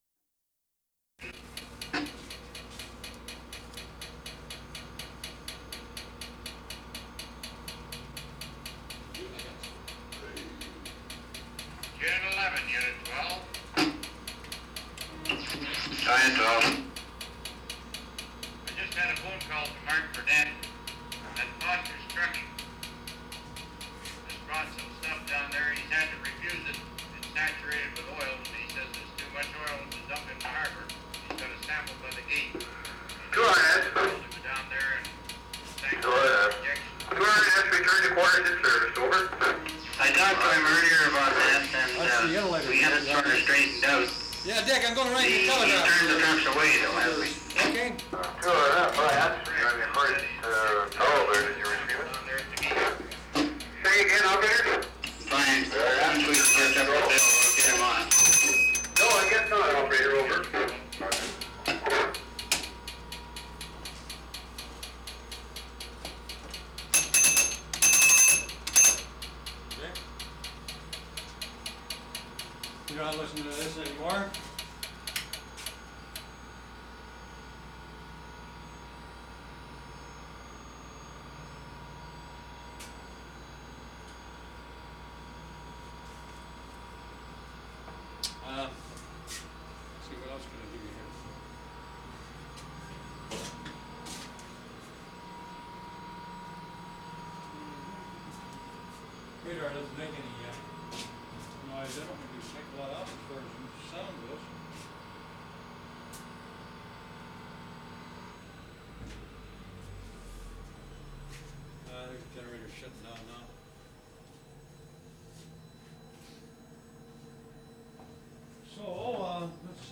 FIREBOAT WHEELHOUSE 3'30"
4. Lively exchange of radio voices, ticking of echo sounds in background throughout.
1'55" generator shuts down.
2'25" change in speaker's voice from inside to outside.
2'30" ship's bell, distorted.